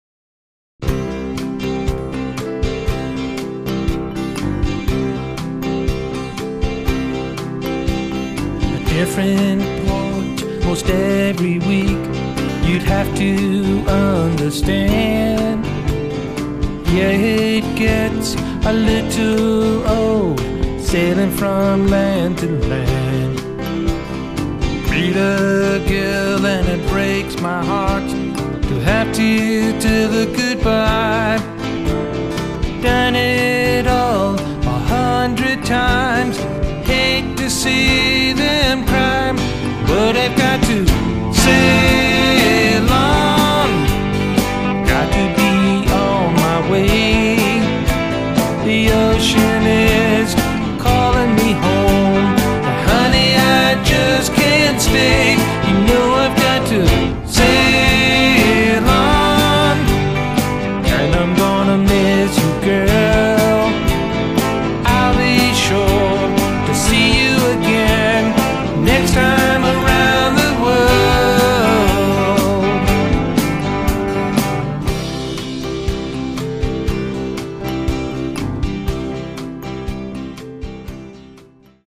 Love the marimbas.  6